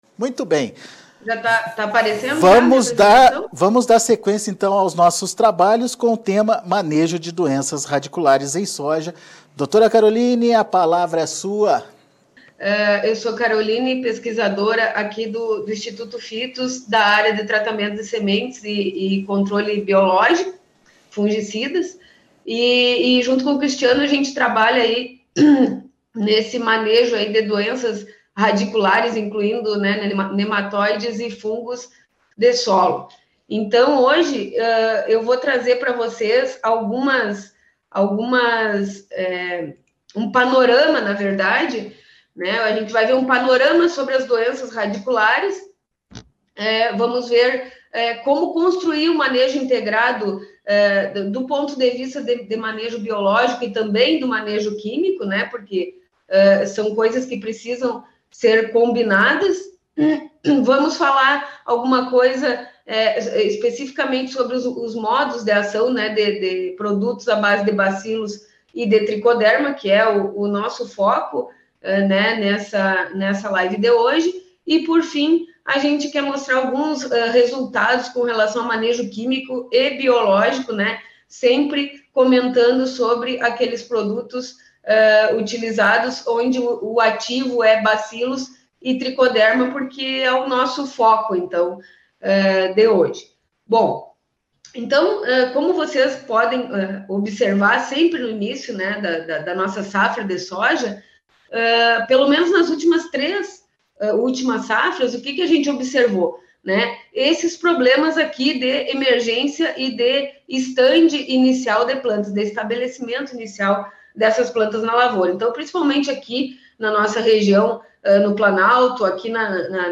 palestra